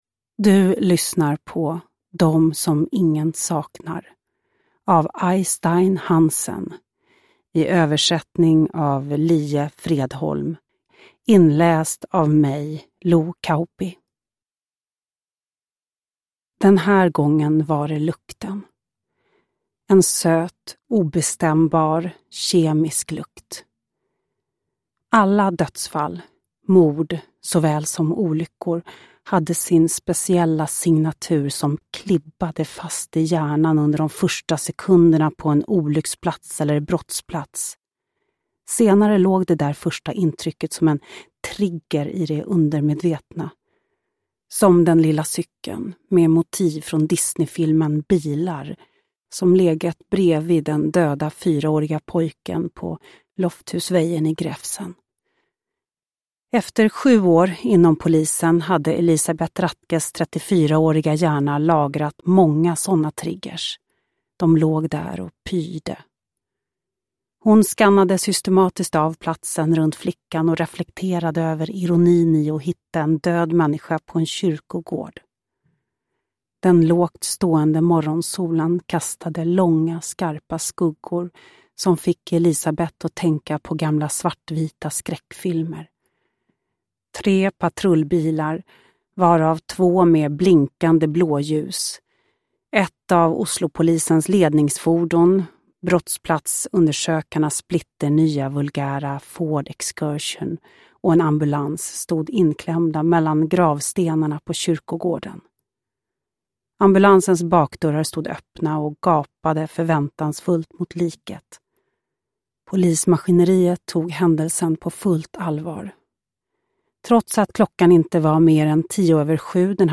Dem som ingen saknar – Ljudbok – Laddas ner
Uppläsare: Lo Kauppi